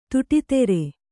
♪ tuṭi tere